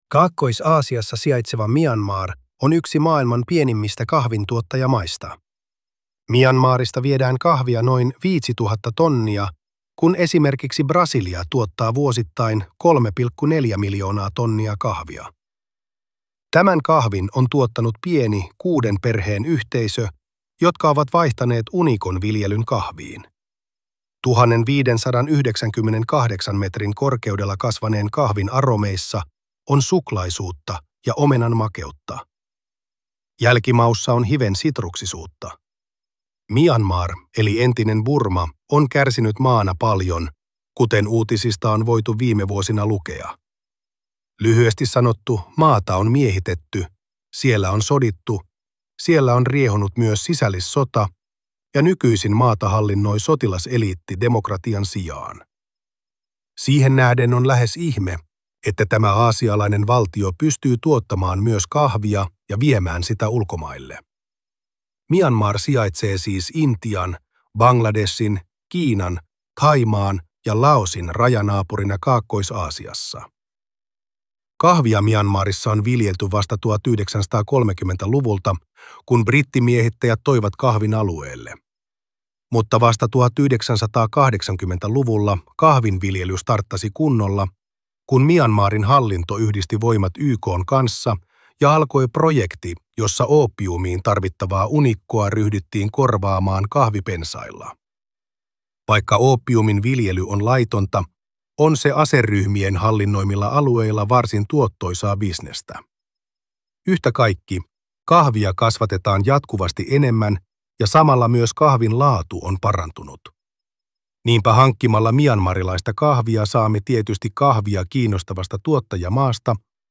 Tarinan äänitiedosto on luotu tekoälyllä.